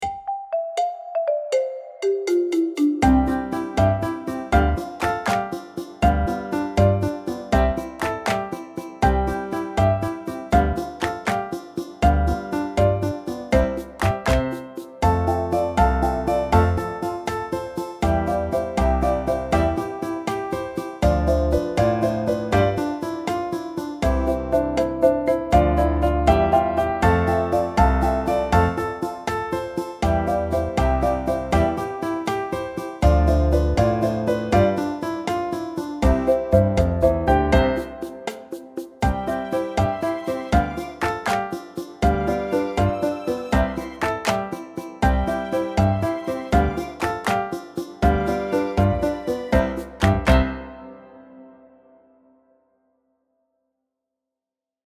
Time Signature: 4/4